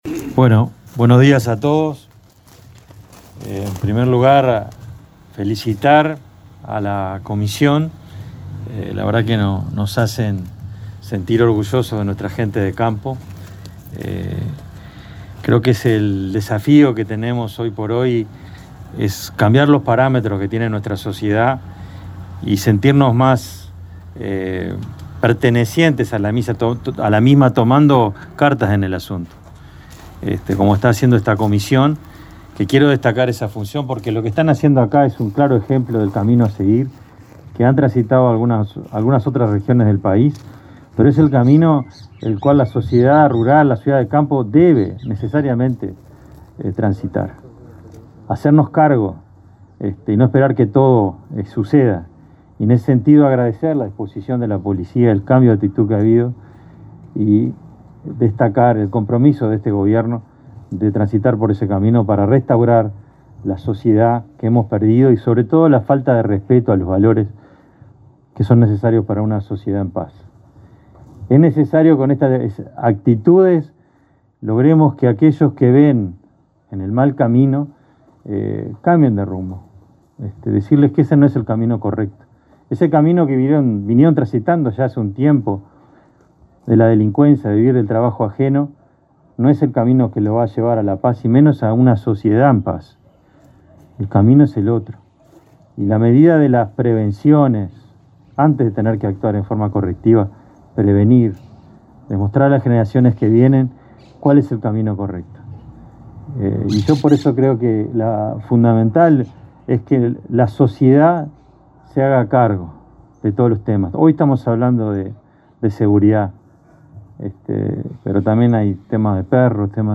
Este jueves 24, los ministros del Interior, Jorge Larrañaga, y de Ganadería, Carlos María Uriarte, encabezaron la ceremonia de entrega de una camioneta para la Brigada de Seguridad Rural en Cerro Colorado, departamento de Florida, y de un dron y binoculares nocturnos donados por la Comisión de Colaboración Policial. Larrañaga recordó que fueron reabiertos 32 destacamentos, comisarías y seccionales en todo el país.